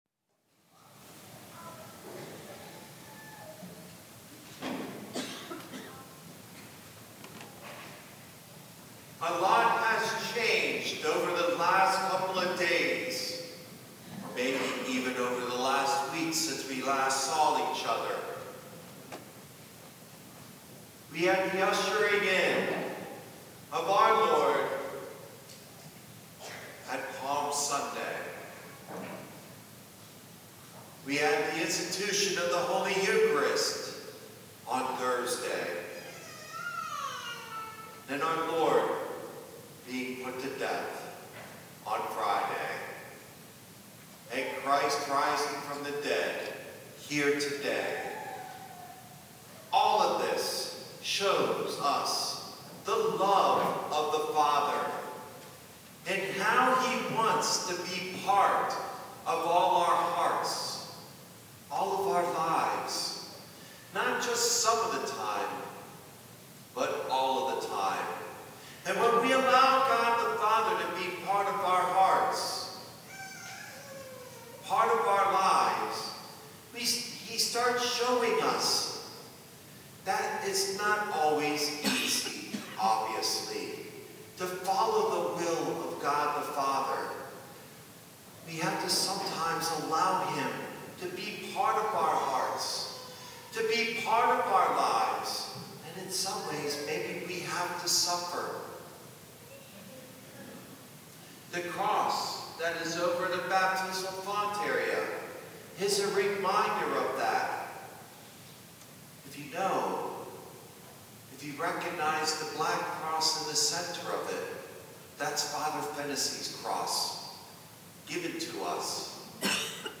easter-homily.mp3